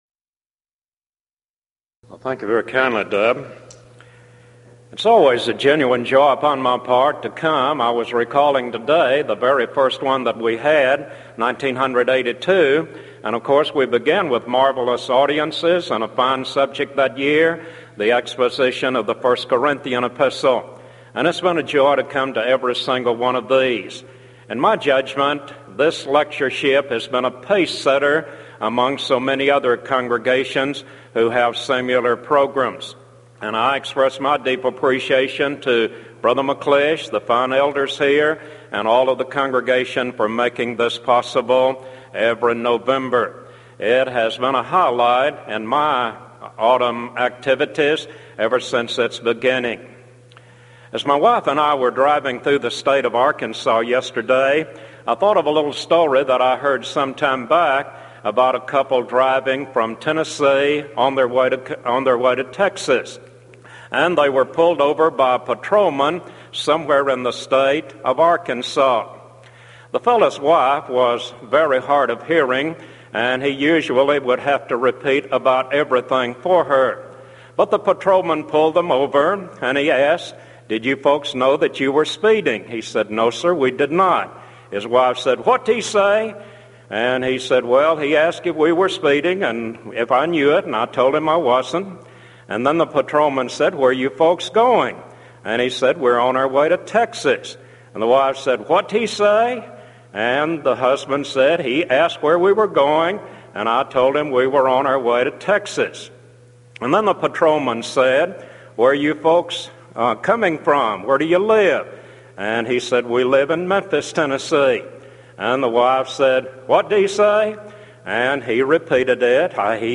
Event: 1993 Denton Lectures